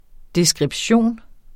Udtale [ desgʁibˈɕoˀn ]